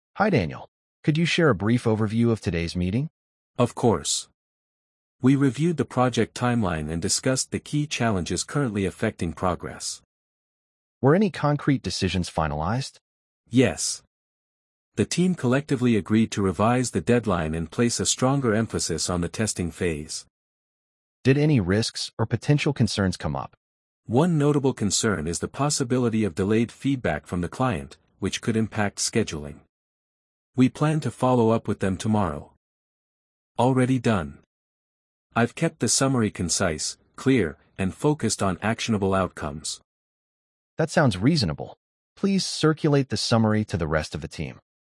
🤝 A lead asks for a quick recap after a team meeting.